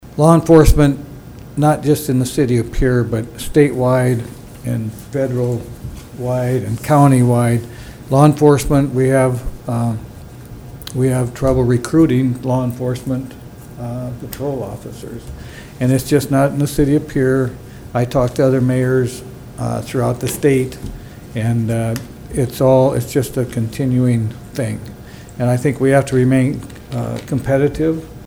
PIERRE, (KCCR) — The Pierre City Commission has approved an across the board pay increase for members of the Pierre Police Department. Mayor Steve Harding says Pierre isn’t the only city dealing with trying to maintain law enforcement staffing numbers….